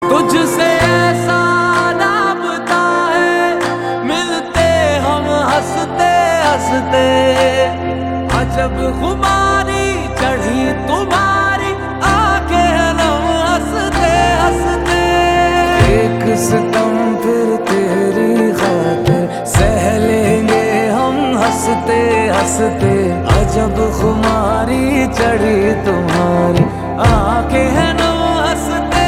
A Melodious Fusion
• Simple and Lofi sound
• Crisp and clear sound